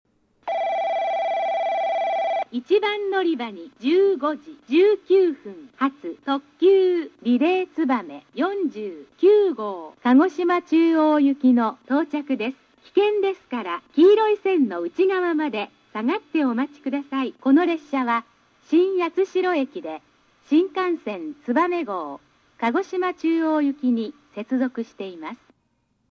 スピーカー： カンノ製作所
音質：E
１番のりば 接近前放送 特急リレーつばめ・鹿児島中央
九州標準放送です。